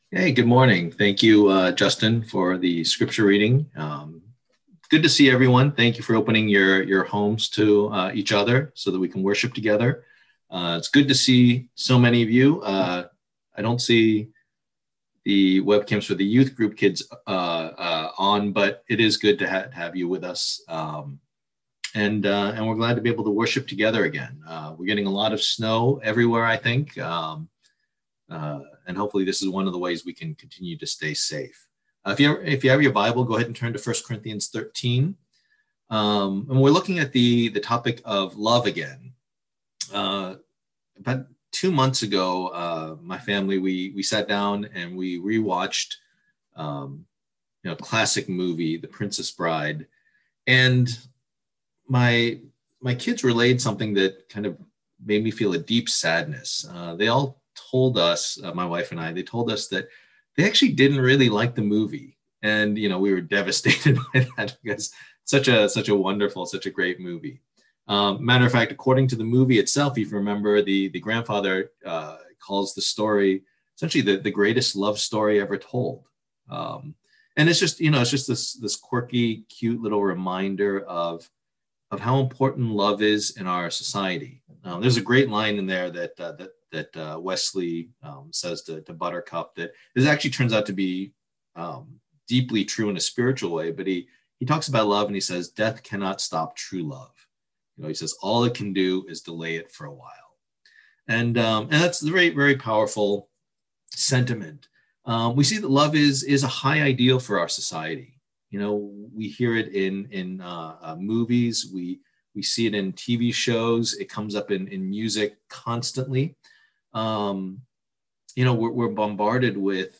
Service Type: Lord's Day